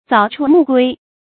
早出暮归 zǎo chū mù guī
早出暮归发音